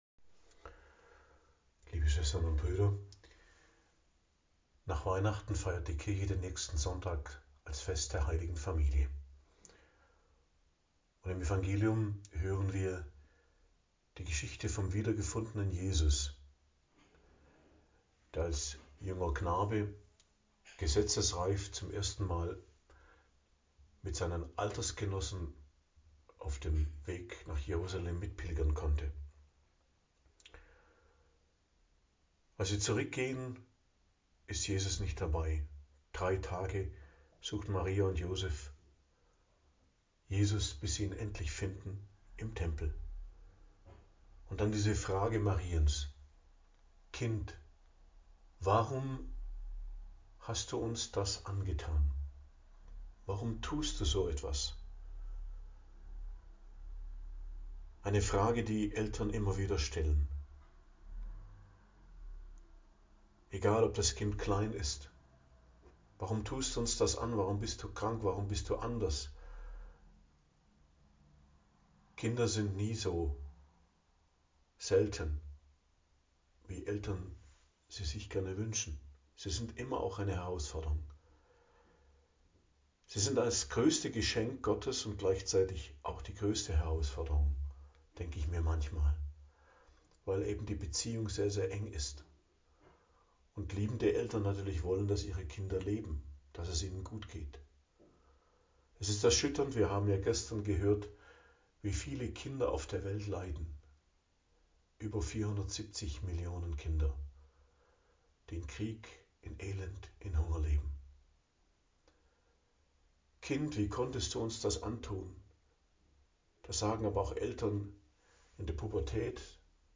Predigt am Fest der Heiligen Familie, 29.12.2024